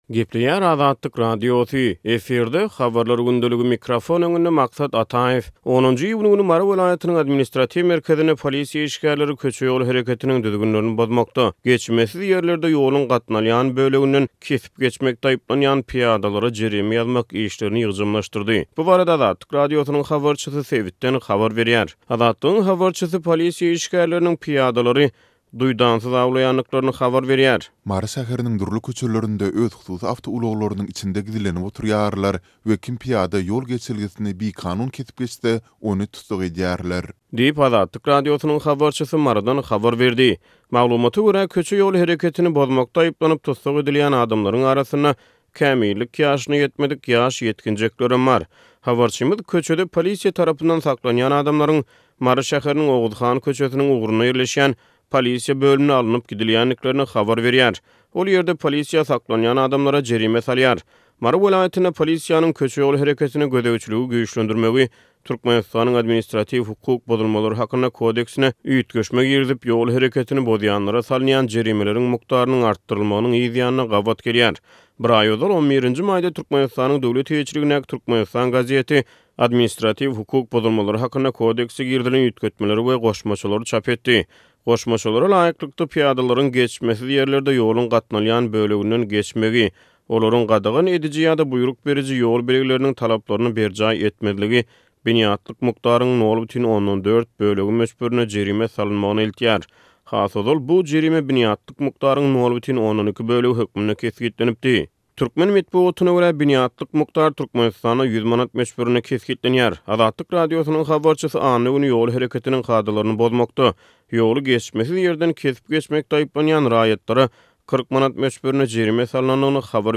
10-njy iýun güni Mary welaýatynyň administratiw merkezinde polisiýa işgärleri köçe ýol hereketiniň düzgünlerini bozmakda, geçmesiz ýerlerde ýoluň gatnalýan böleginden kesip geçmekde aýyplanýan pyýadalara jerime ýazmak işlerini ýygjamlaşdyrdy. Bu barada Azatlyk Radiosynyň habarçysy sebitden habar berýär.